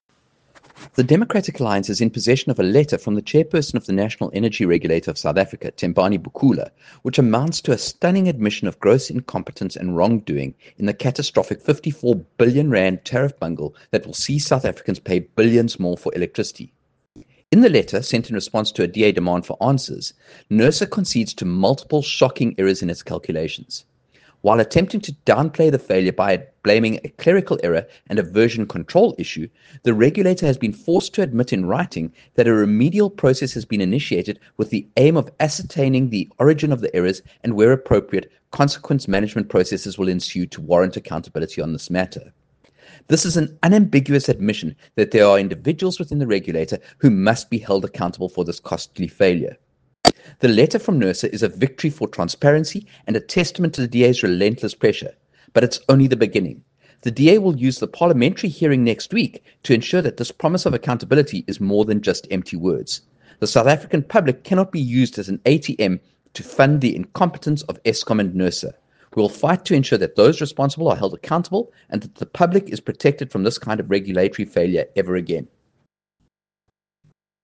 Soundbite by Kevin Mileham MP